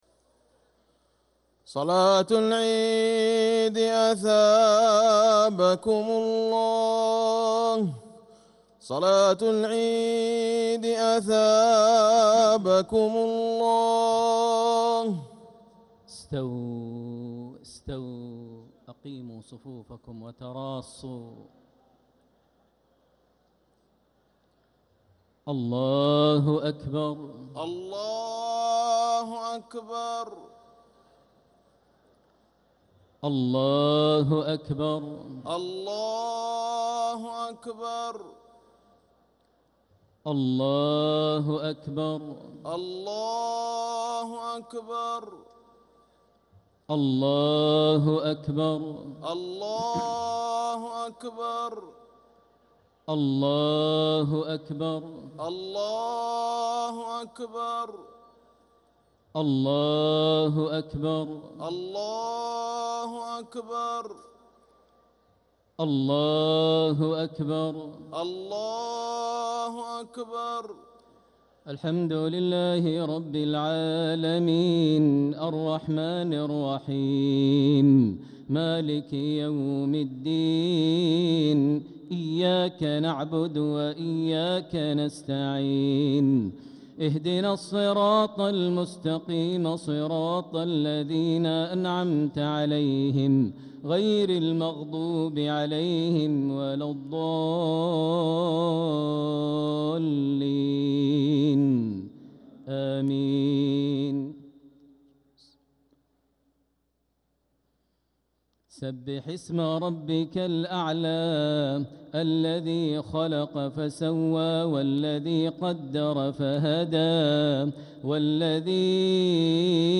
صلاة عيد الأضحى 1446هـ سورتي الأعلى و الغاشية كاملة | Eid prayer Surah Al-a’ala and Al-gashiya 6-6-2026 > 1446 🕋 > الفروض - تلاوات الحرمين